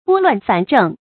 注音：ㄅㄛ ㄌㄨㄢˋ ㄈㄢˇ ㄓㄥˋ
撥亂反正的讀法